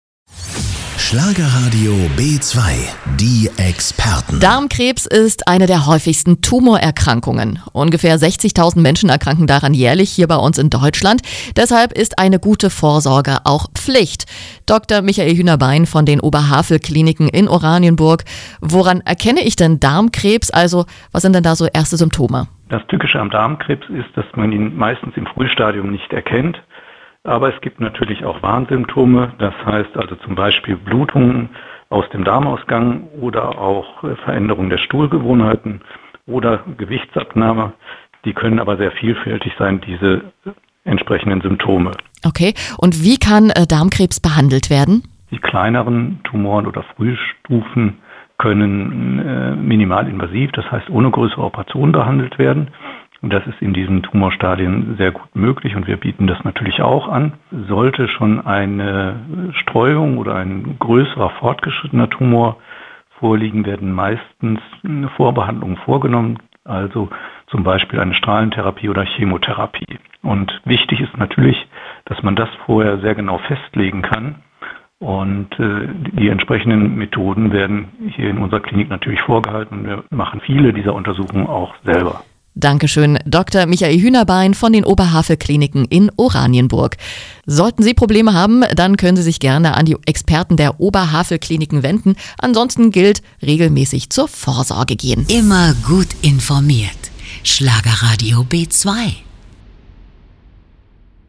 im Interview bei Schlagerradio.